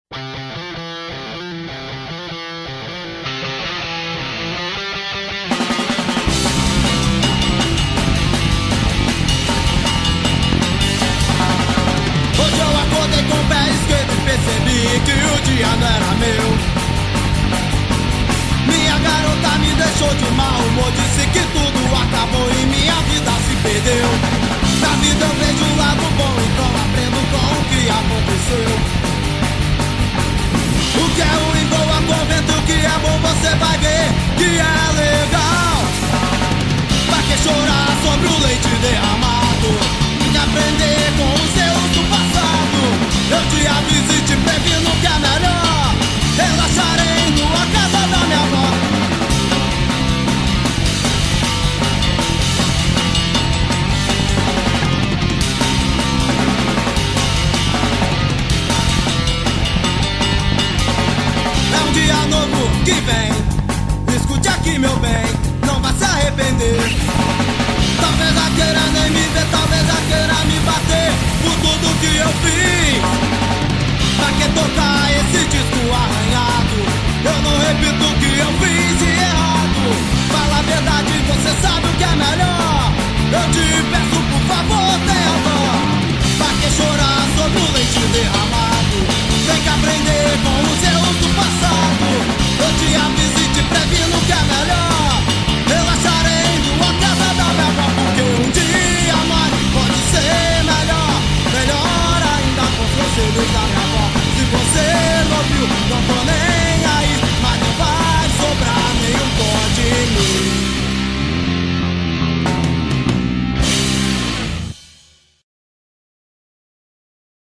EstiloHardcore